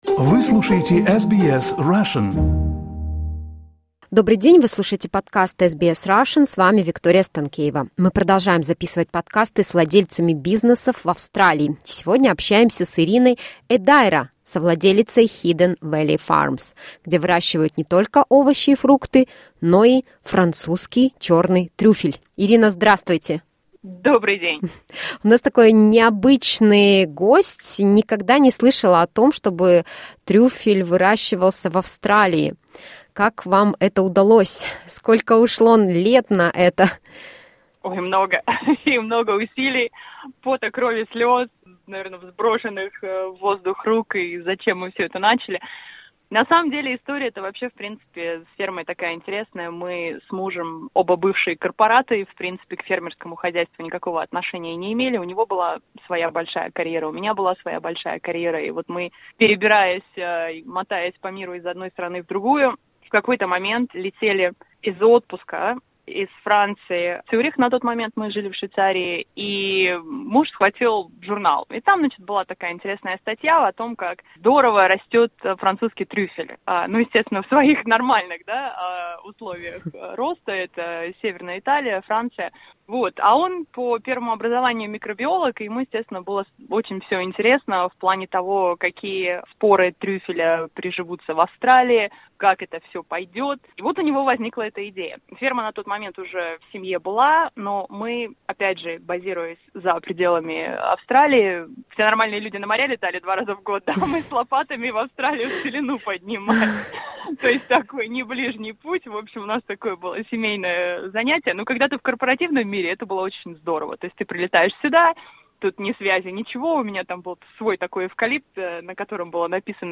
Это первая часть нашего интервью.